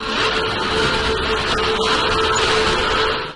噪声循环播放 " 扫频循环播放01
描述：环境噪音循环，用多个循环和其他声音单独处理的顺序，然后混合下来，再送去另一轮处理。
Tag: 环境 工业 噪声 处理抽象